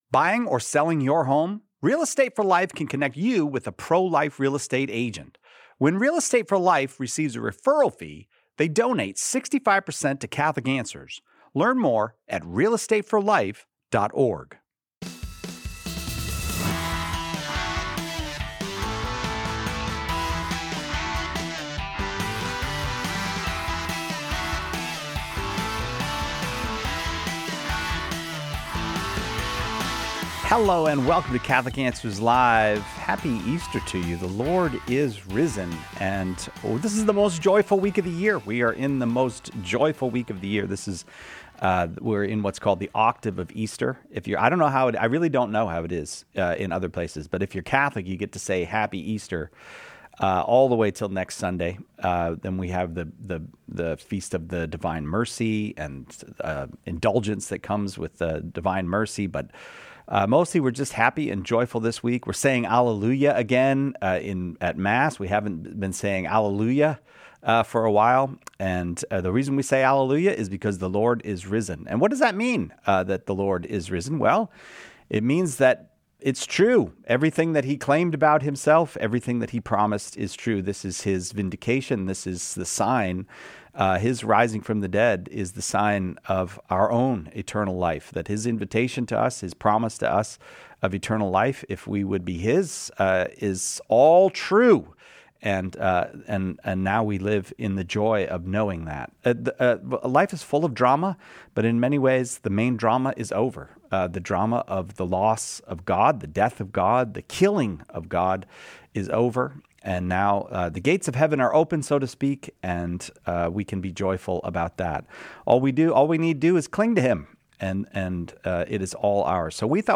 In this episode of Catholic Answers Live , Catholic Answers apologists walk through the key post-Resurrection appearances of Christ, from the Upper Room to the road to Emmaus and beyond. They explain what happened during the 40 days leading up to the Ascension, the significance of Jesus appearing to the apostles, and how these encounters strengthen the case for the Resurrection. The discussion also covers the Ascension from the Mount of Olives and Christ’s later appearance to St. Paul on the road to Damascus.